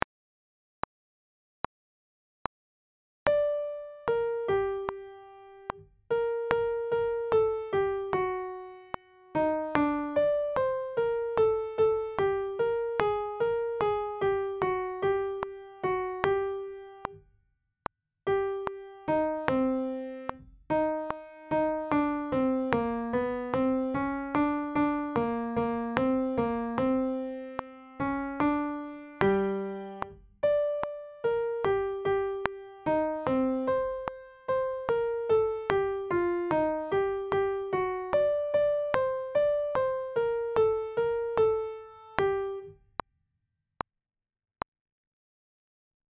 Wordless-Round_Caldara.mp3